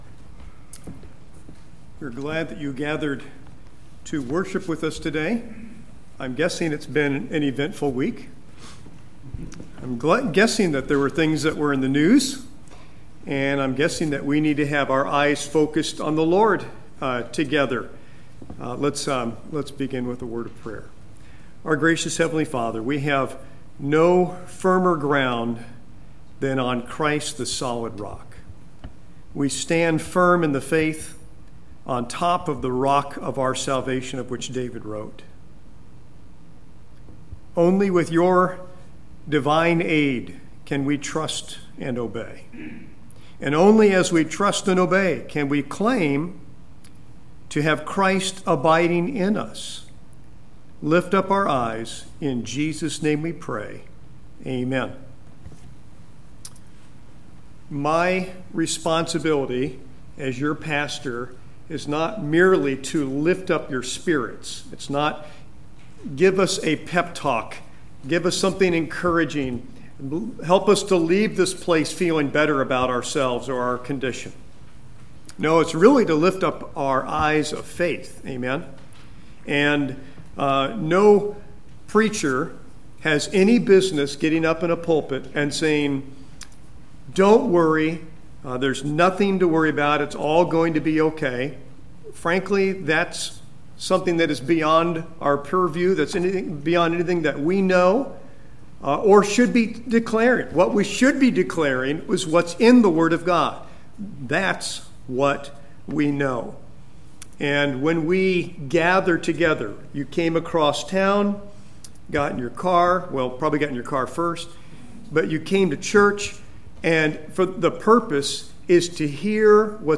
Service Sunday Morning